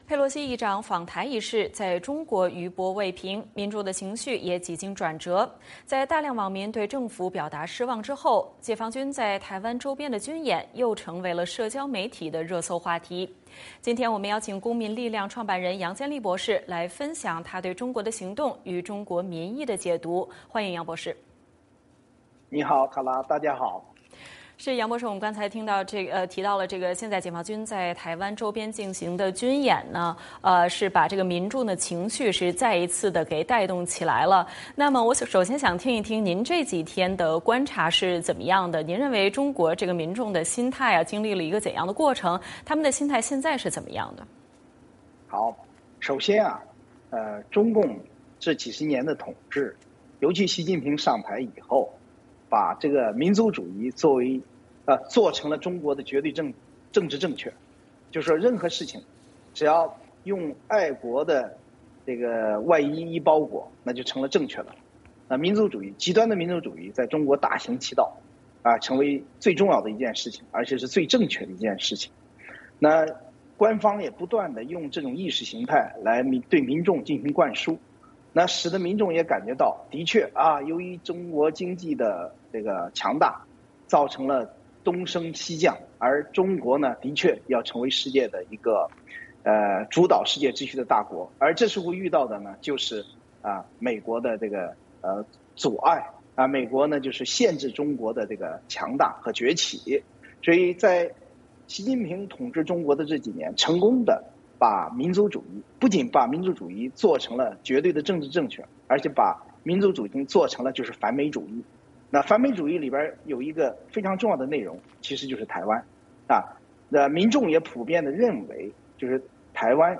佩洛西议长访台一事在中国余波未平，民众的情绪也几经转折，在大量网民对政府表达失望之后，解放军在台湾周边的军演.又成为了社交媒体的热搜话题。今天我们邀请公民力量创办人杨建利博士，分享他对中国的行动与中国民意的解读。